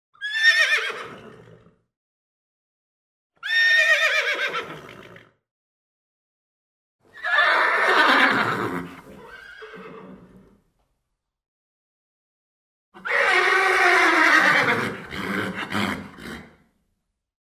Звуки ржания лошади
Стандартное ржание лошади